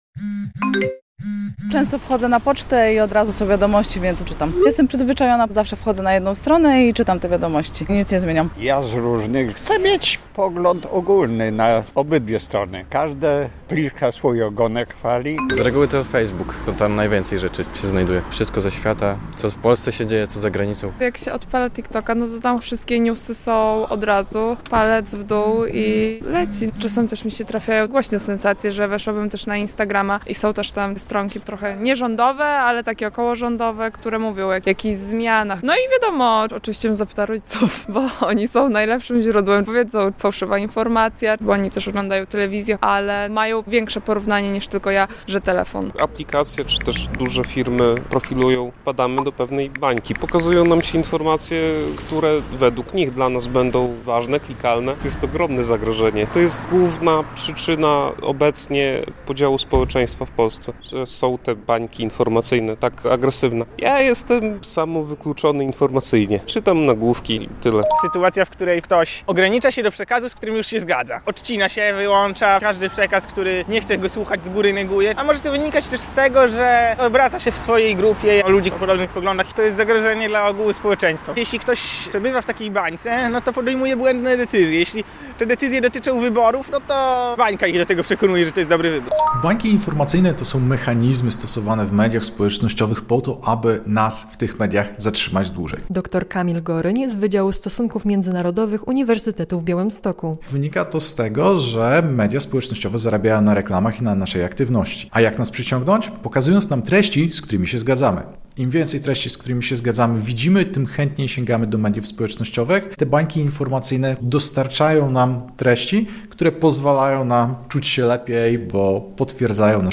Słuchaj: Z jakich źródeł informacji najczęściej korzystają Białostoczanie? - relacja